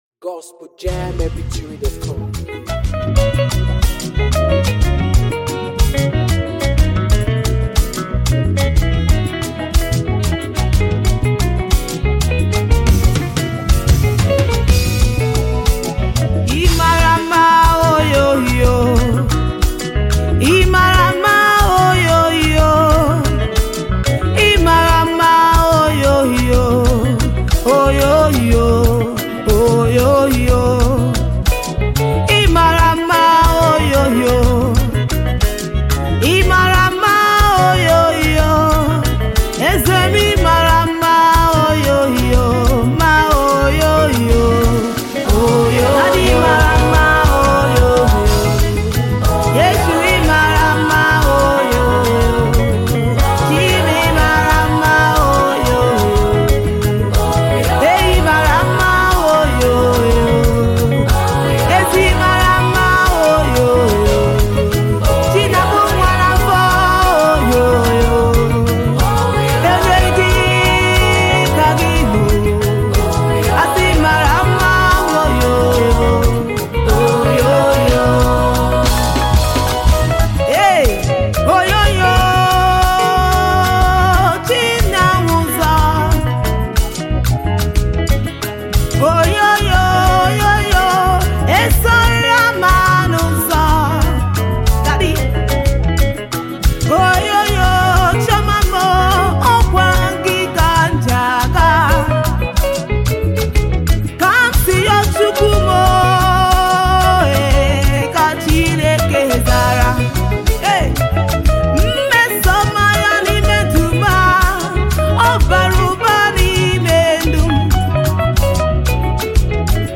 African praiseAfro beatmusic